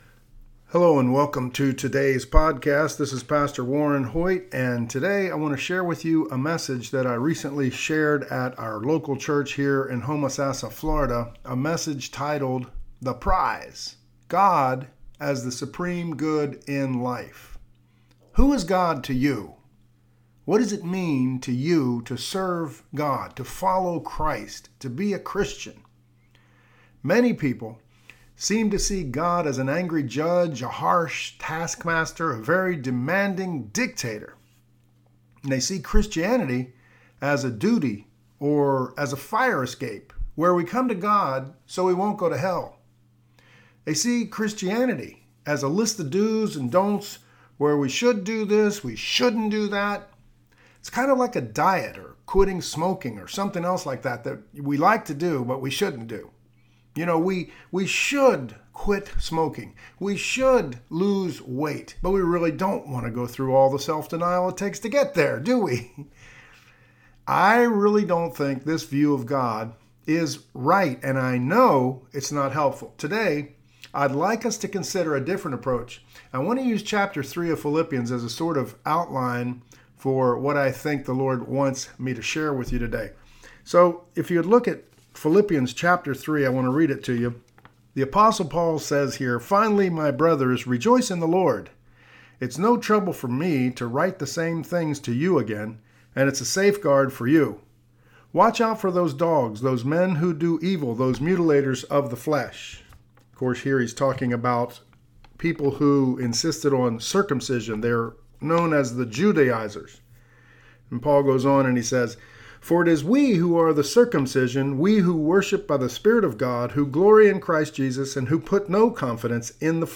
Last Sunday, I was asked to share the message at our local church, here in Homosassa. I thought I'd make an audio file of that sermon available to you.